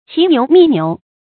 骑牛觅牛 qí niú mì niú 成语解释 见“骑驴觅驴”。
ㄑㄧˊ ㄋㄧㄨˊ ㄇㄧˋ ㄋㄧㄨˊ